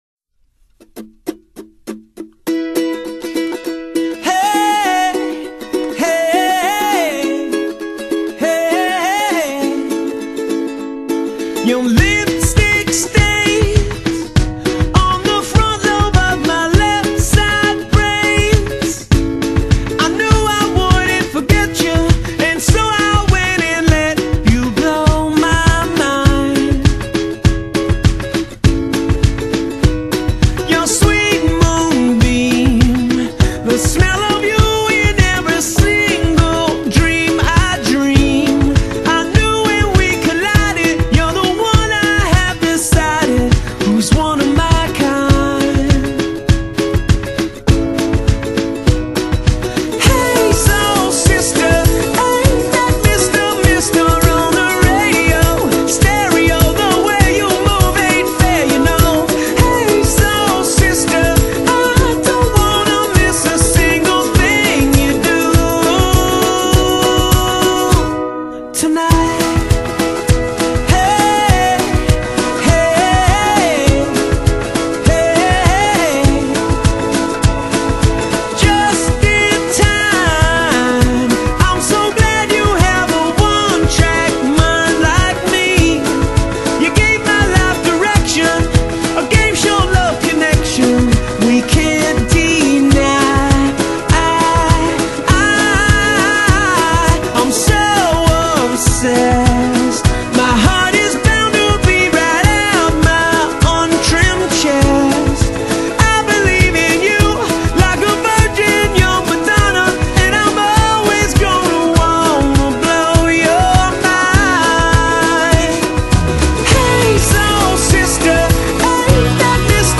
Genre: Pop, Rock, Dance